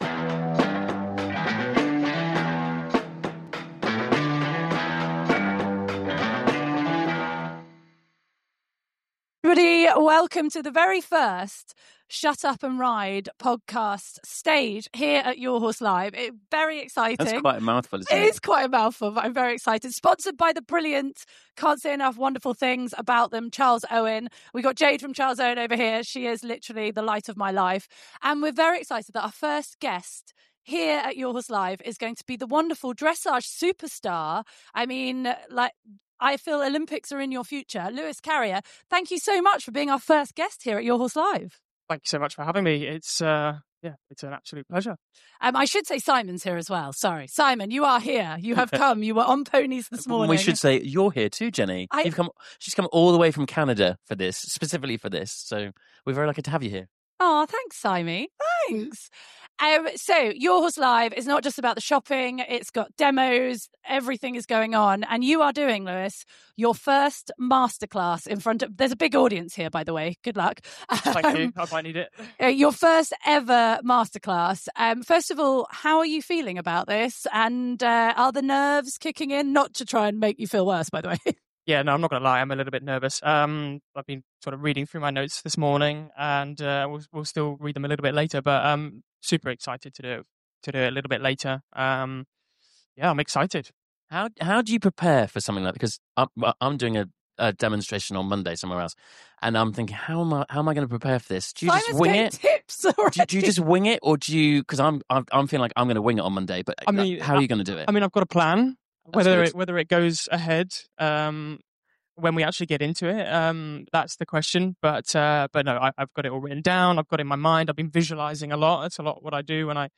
LIVE from the Stage at Your Horse Live In this unforgettable live podcast recording